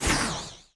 gale_dry_fire_01.ogg